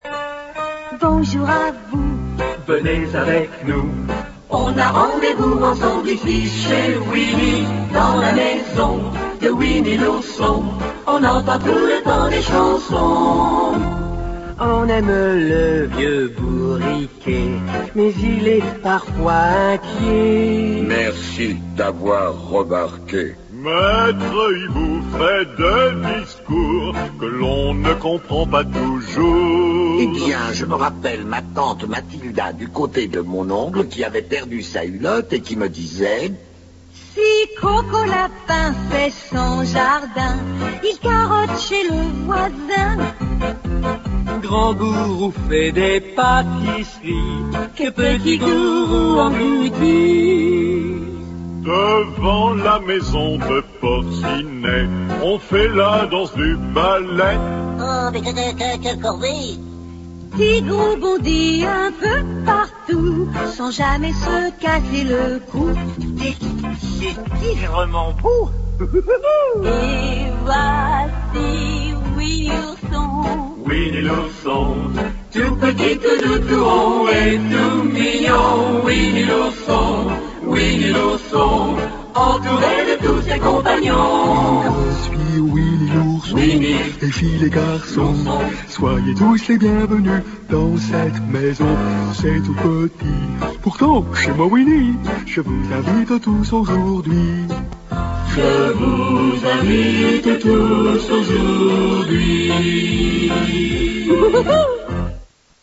Version française du générique de début de la série.